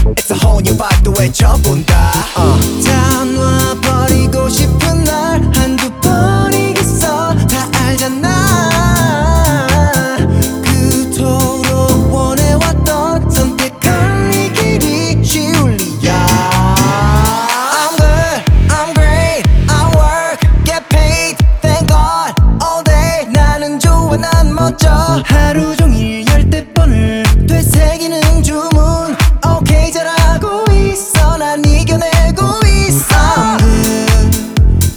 Жанр: K-pop / Поп / Русские